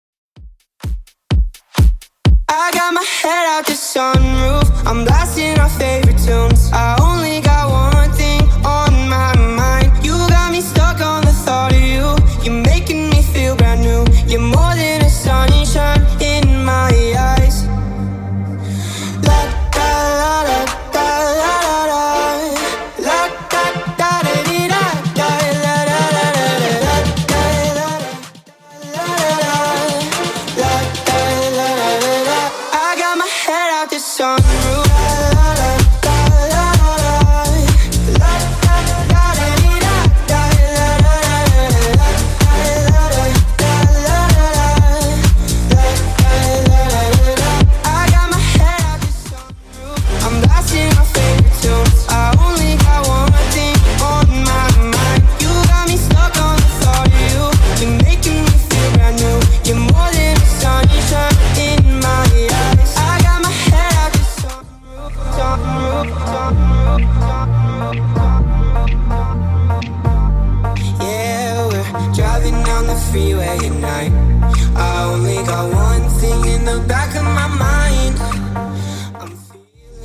BPM: 127 Time